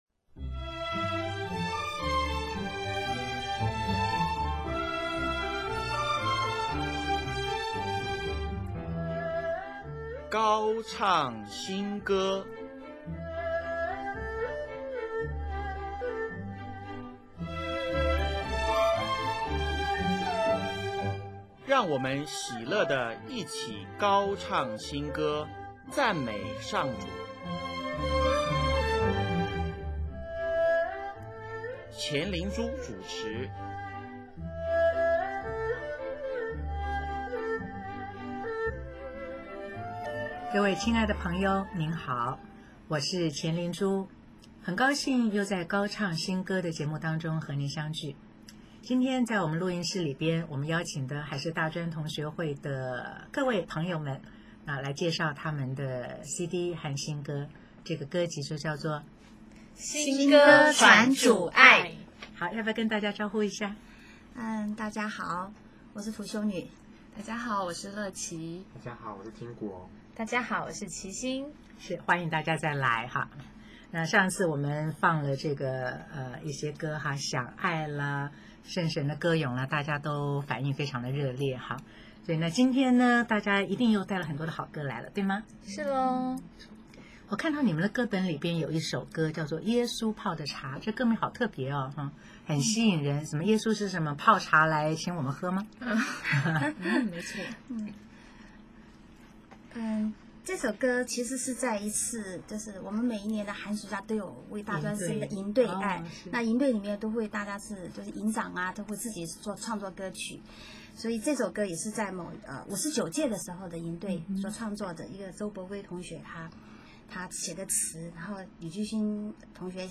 “耶稣泡的茶”，从自己的感受出发，带抒情味。曲风特别，流行于年轻人之中。
“感谢讚美心”，温柔、轻快、上口。